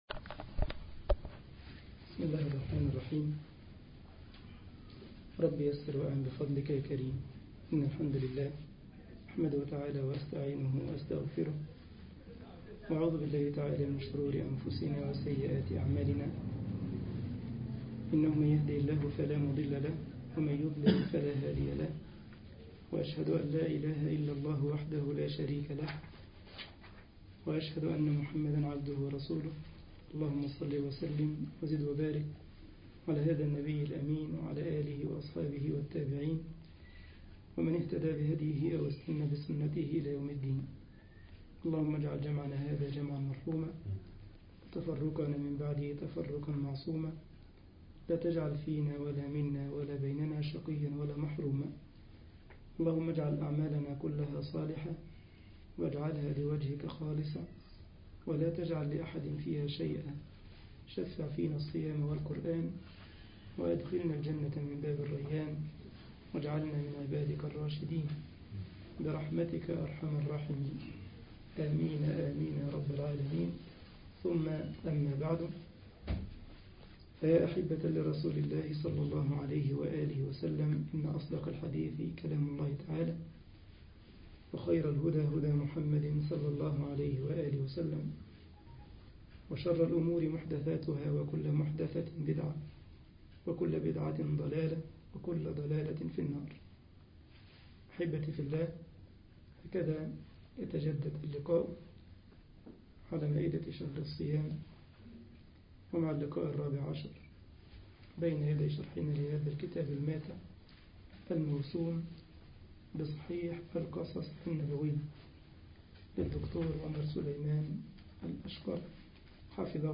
مسجد الجمعية الإسلامية بالسارلند ـ ألمانيا درس 08 رمضان 1433 هـ